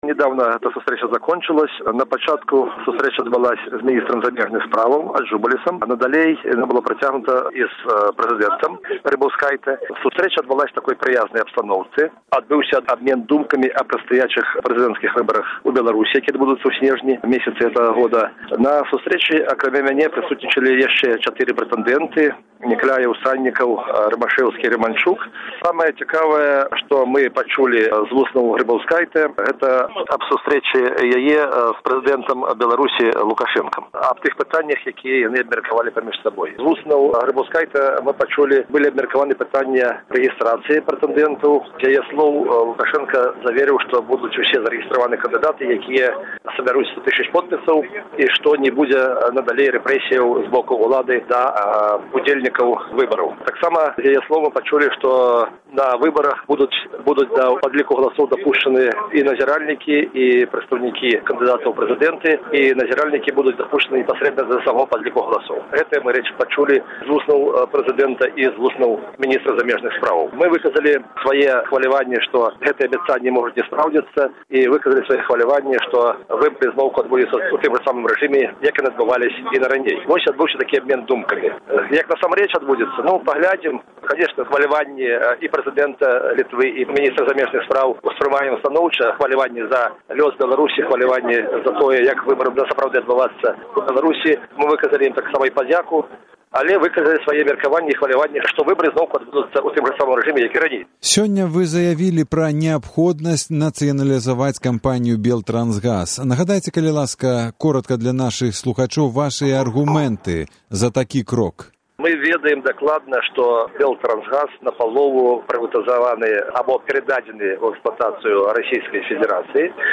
Гутарка з Рыгорам Кастусёвым